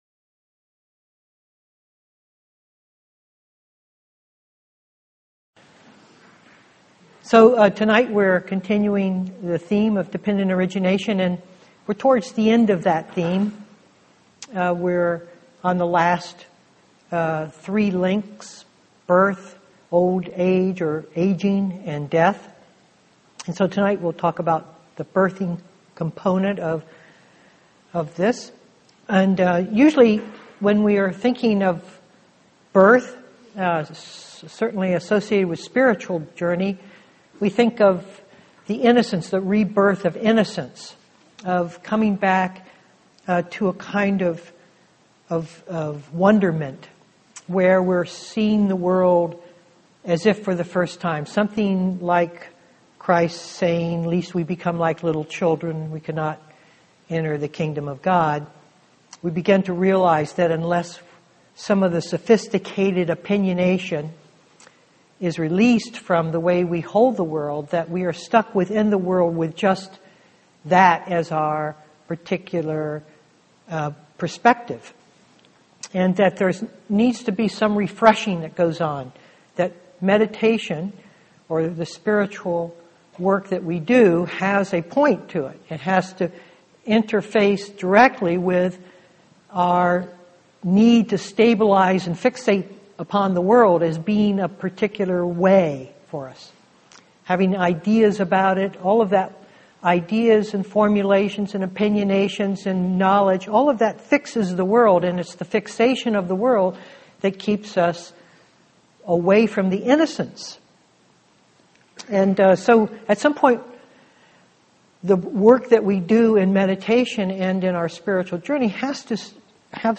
2013-10-22 Venue: Seattle Insight Meditation Center